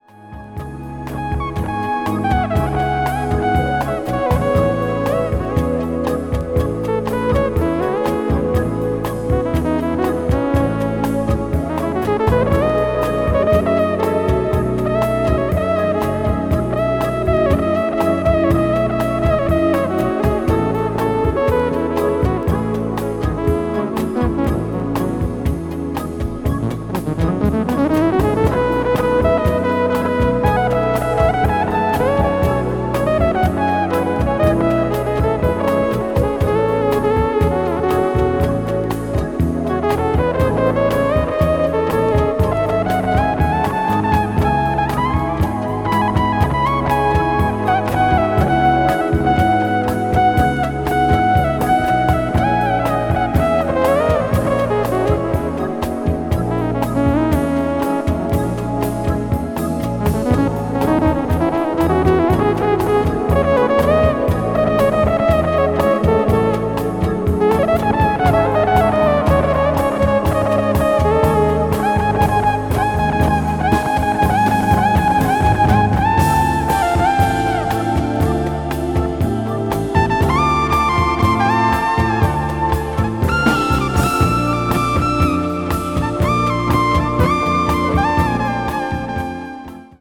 media : EX/EX(わずかにチリノイズが入る箇所あり)
contemporary jazz   crossover   ethnic jazz   fusion